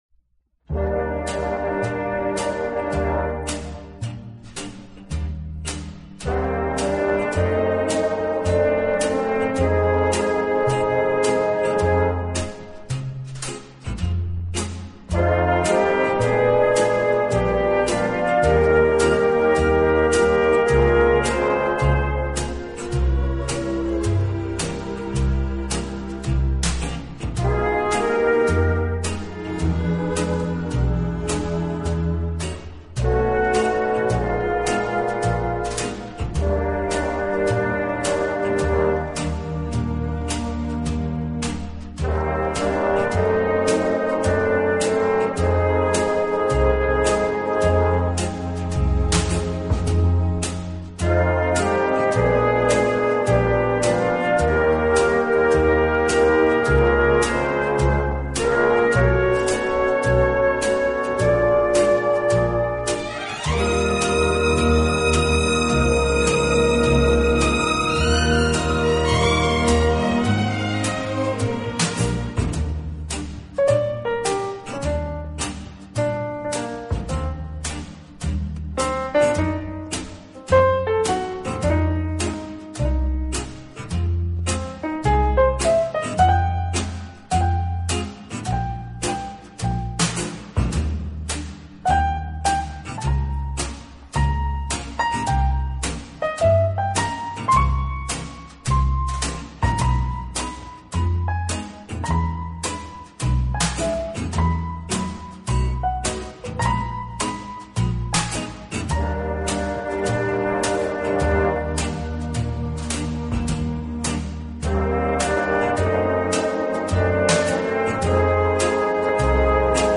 小号的音色，让他演奏主旋律，而由弦乐器予以衬托铺垫，音乐风格迷人柔情，声情并
温情、柔软、浪漫是他的特色，也是他与德国众艺术家不同的地方。